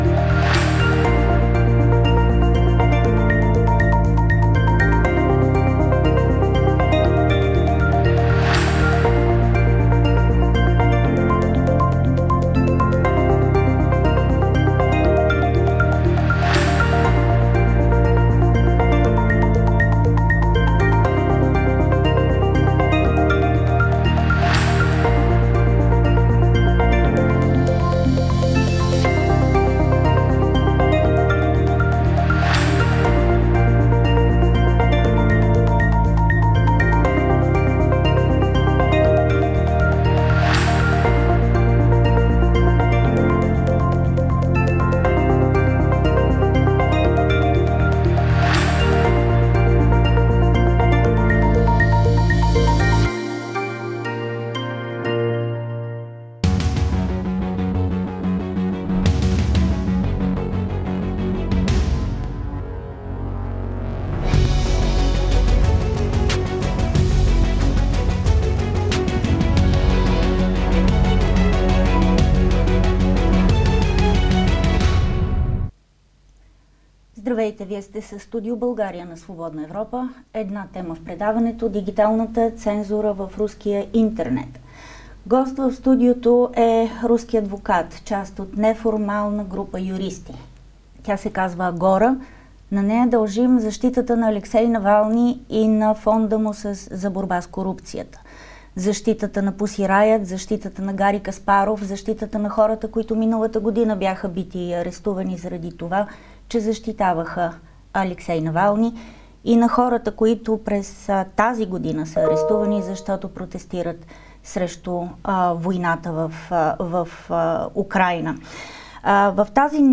Как се стигна до руската цензура в интернет? Чуйте разговора